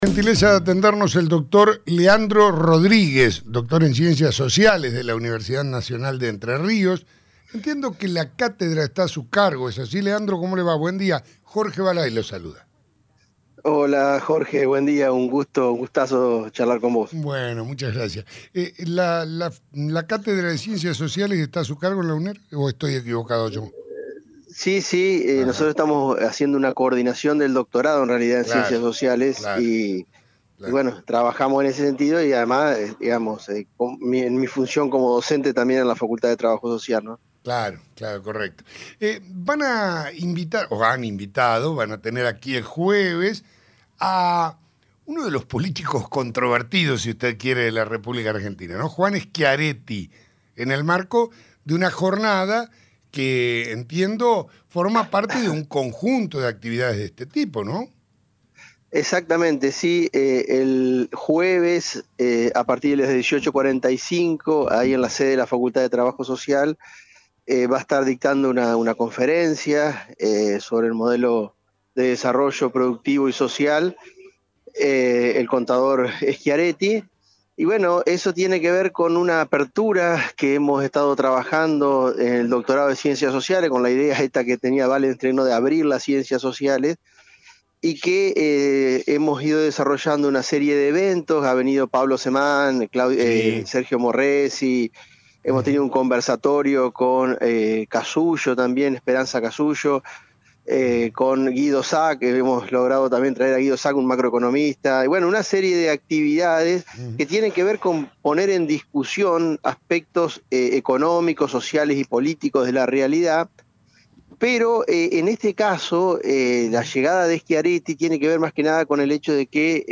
en diálogo con el programa Sexto Sentido